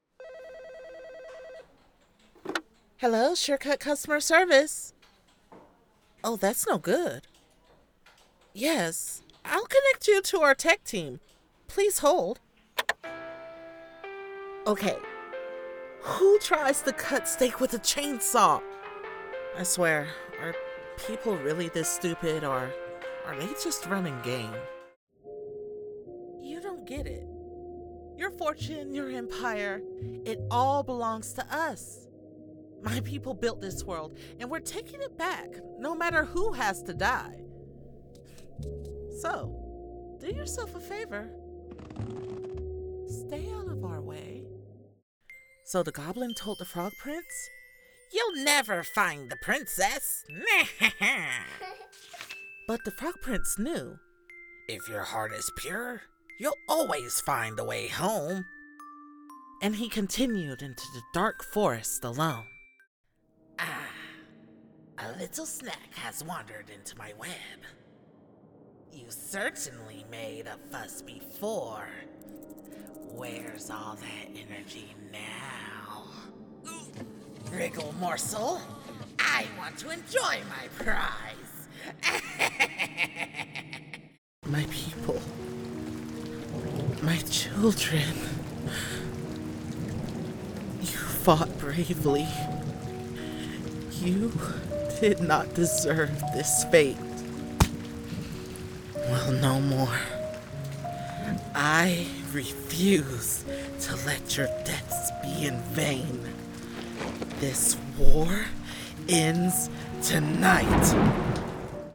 Female
English (North American)
Adult (30-50), Older Sound (50+)
Character / Cartoon